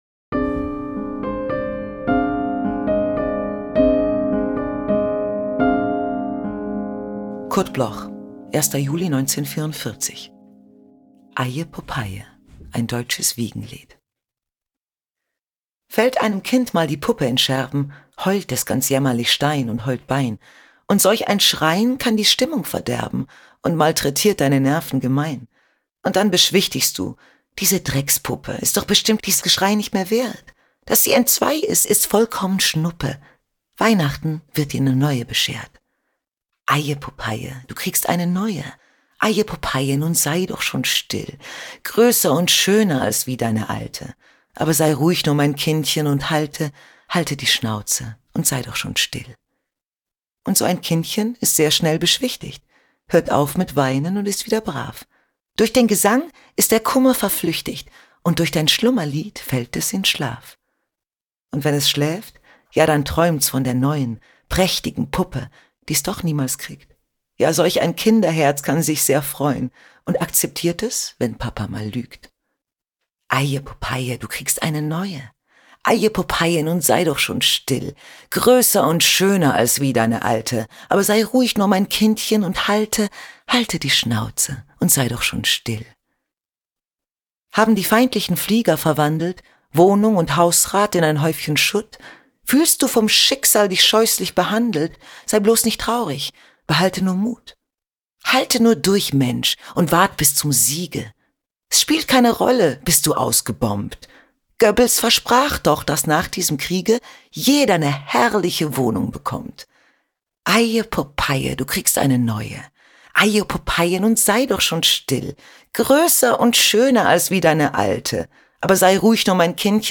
„Eie Popeie“ – ein Deutsches Wiegenlied
Carol-Schuler-Eia-Popeie-mit-Musik_raw.mp3